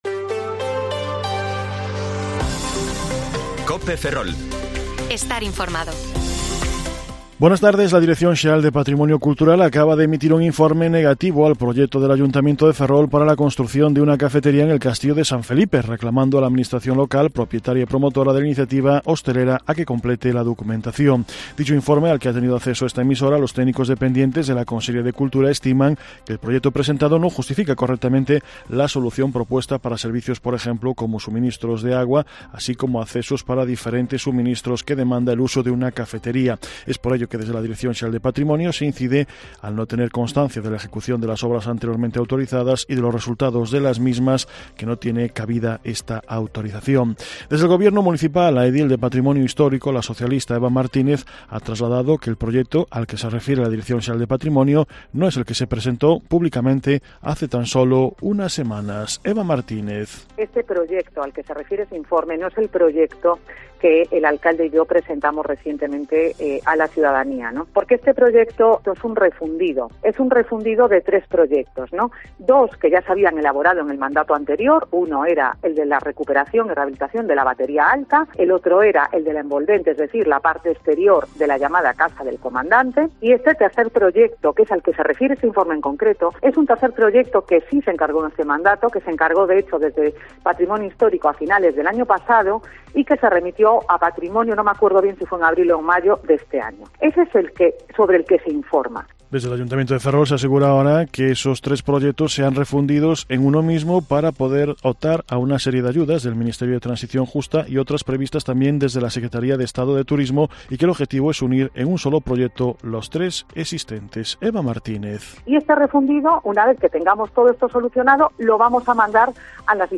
Informativo Mediodía COPE Ferrol 19/10/2022 (De 14,20 a 14,30 horas)